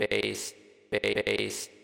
贝司声乐切片
Tag: 130 bpm House Loops Vocal Loops 319.04 KB wav Key : Unknown